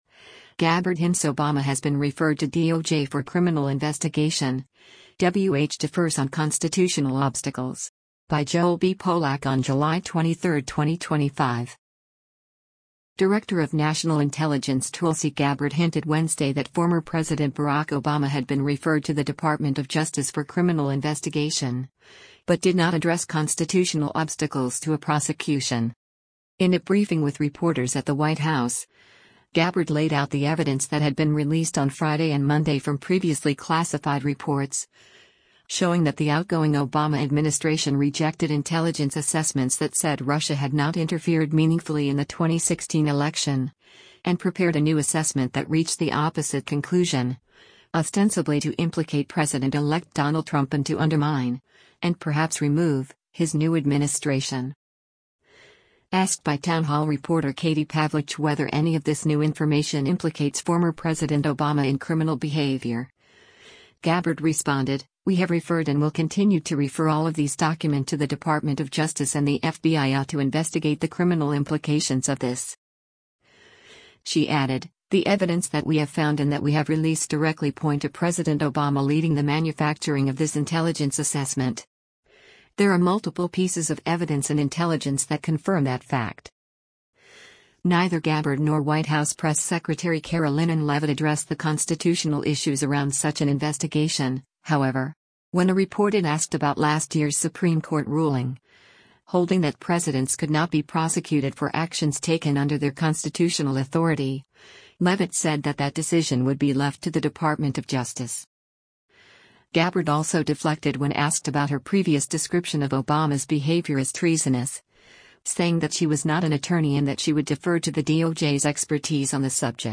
In a briefing with reporters at the White House, Gabbard laid out the evidence that had been released on Friday and Monday from previously classified reports, showing that the outgoing Obama administration rejected intelligence assessments that said Russia had not interfered meaningfully in the 2016 election, and prepared a new assessment that reached the opposite conclusion, ostensibly to implicate President-elect Donald Trump and to undermine — and perhaps remove — his new administration.